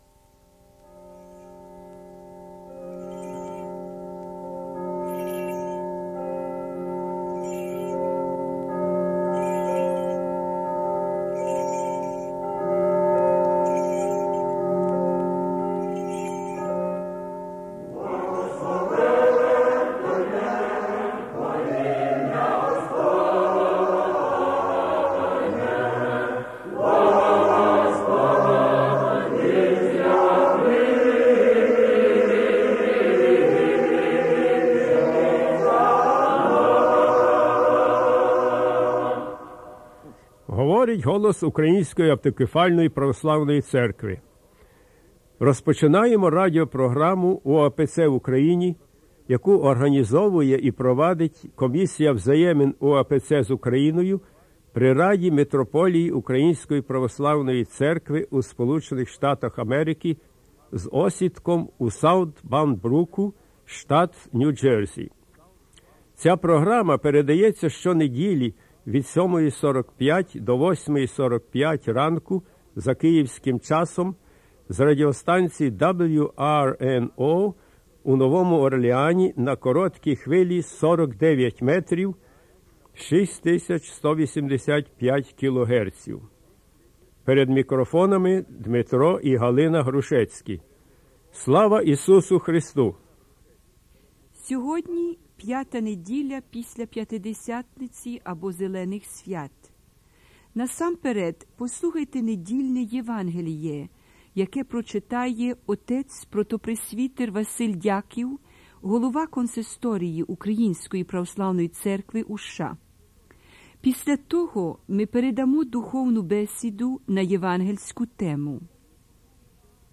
Gospel reading and "spiritual discussion"
Choral rendition of the conclusion of the Divine Liturgy